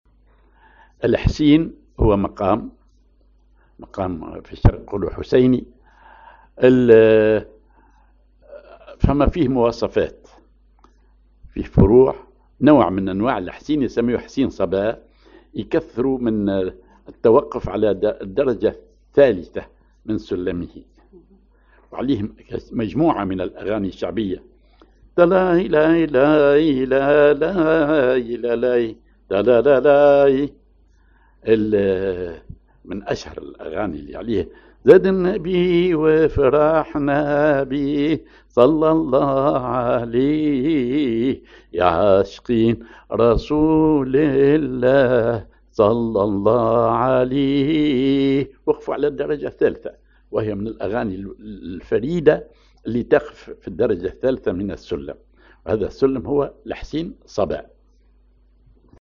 Maqam ar حسين صبا
Rhythm ID سماعي ثقيل
genre سماعي